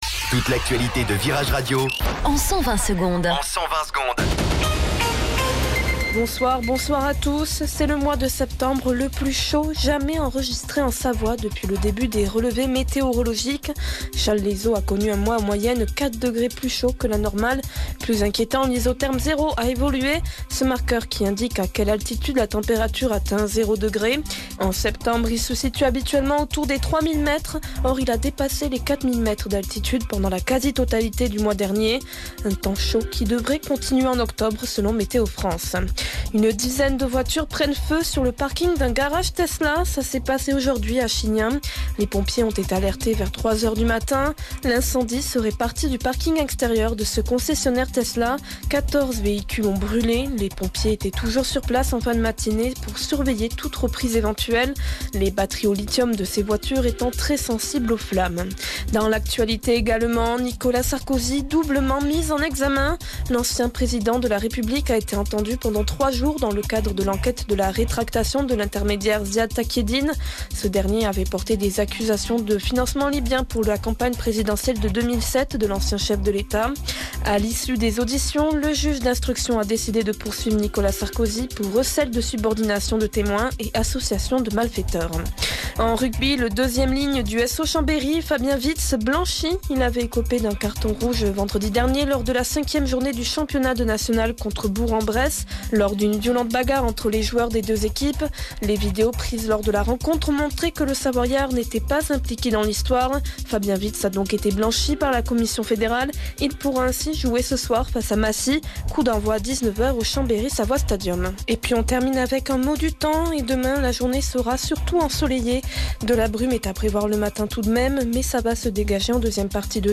Flash Info Chambéry